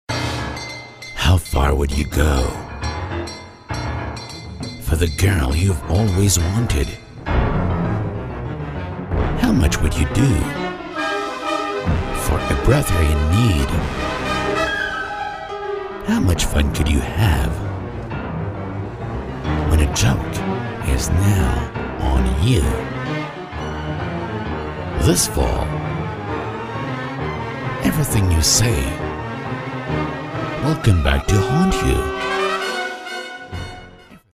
Thriller Movie Trailer